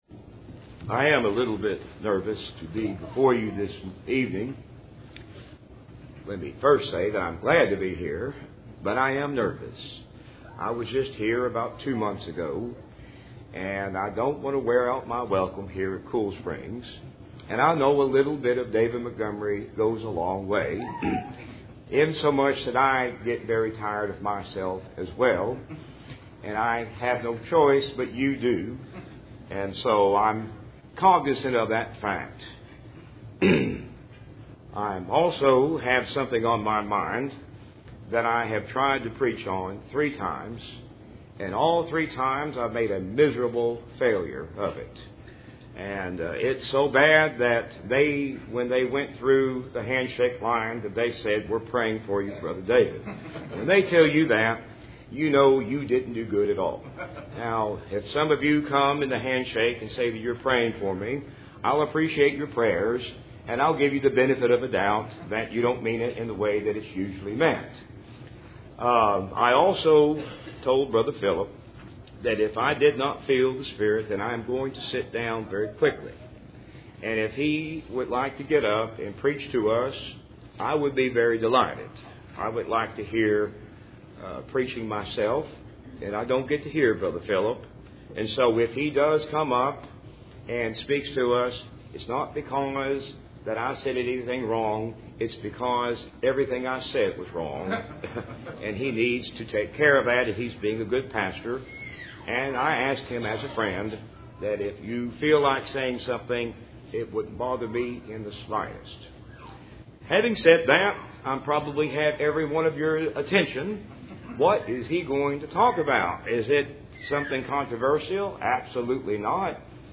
Matthew 3:7-17 Service Type: Cool Springs PBC Sunday Evening %todo_render% « Past